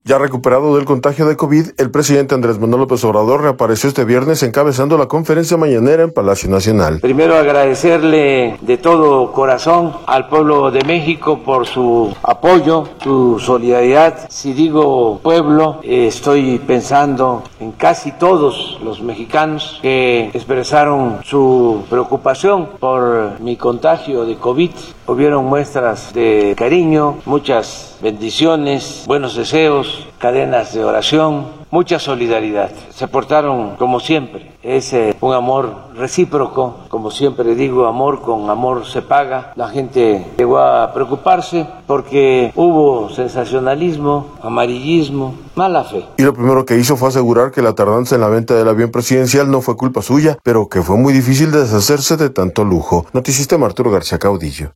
Ya recuperado del contagio de Covid, el presidente Andrés Manuel López Obrador, reapareció este viernes encabezando la conferencia Mañanera en Palacio Nacional.